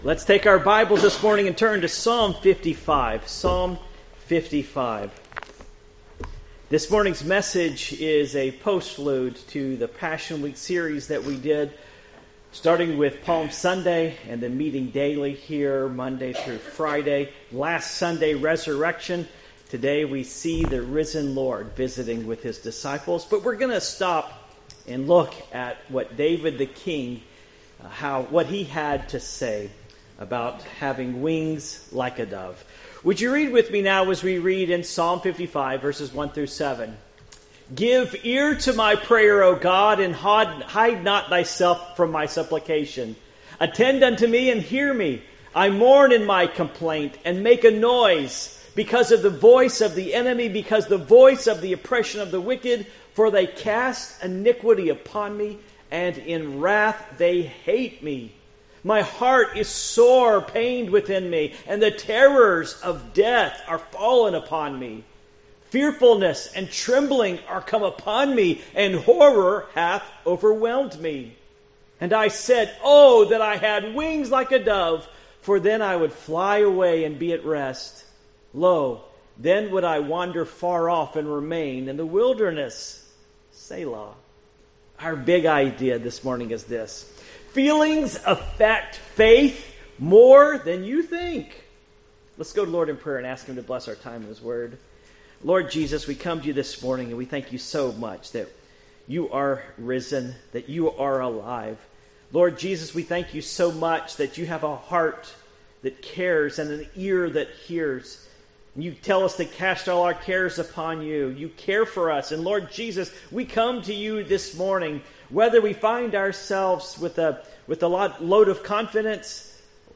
Psalm 55:1-7 Service Type: Morning Worship Psalms 55:1-7 1 To the chief Musician on Neginoth